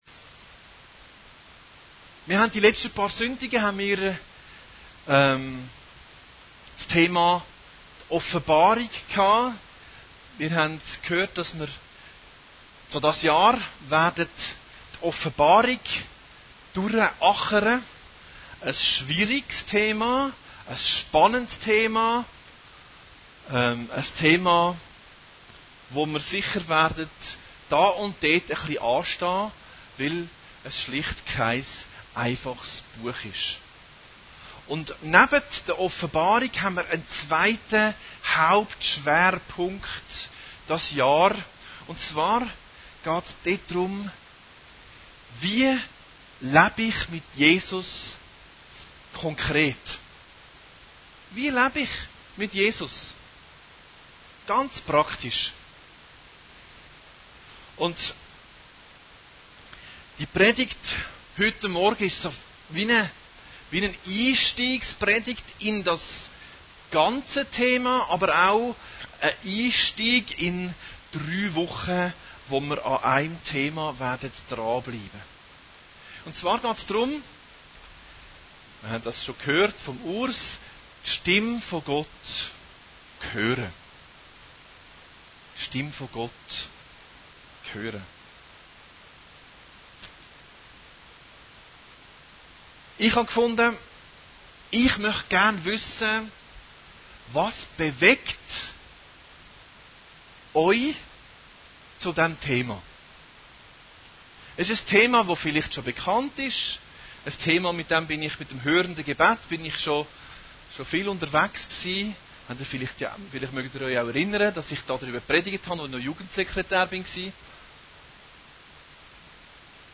Predigten Heilsarmee Aargau Süd – Das Vertauensverhältnis